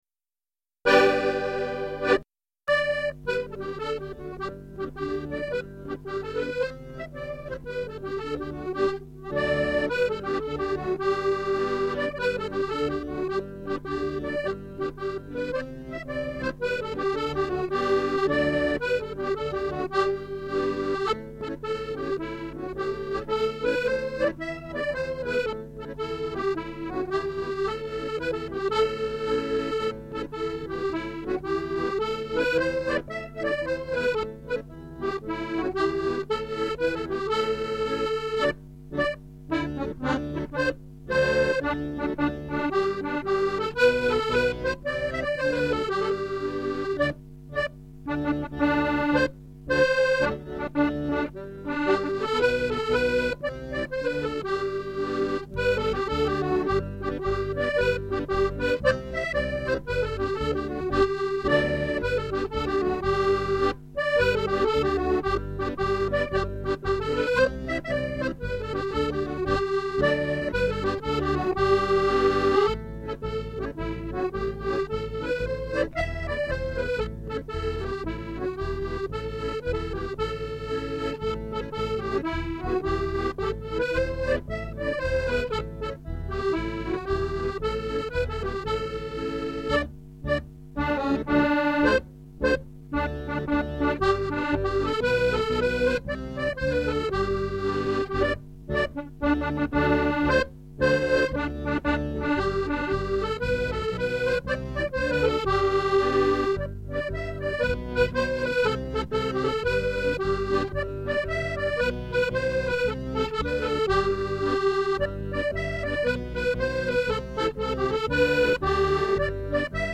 Music - 48 bar jigs or reels